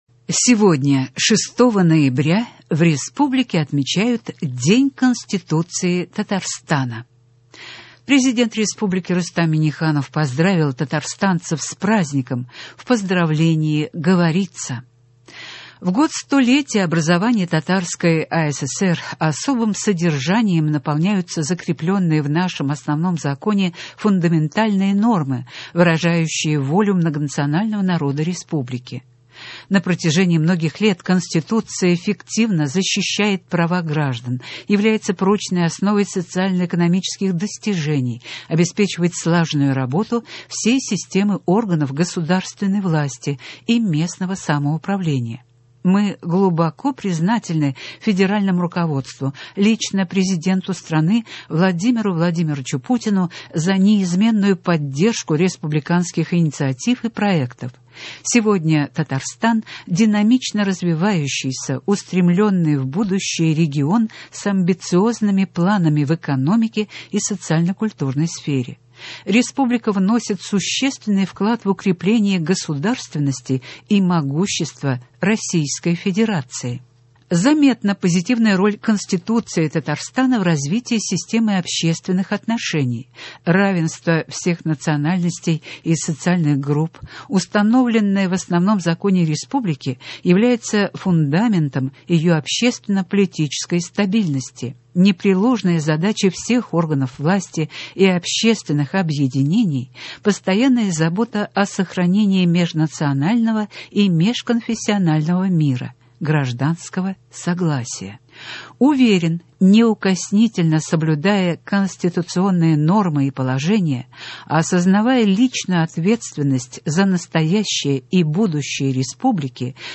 Новости (06.11.20)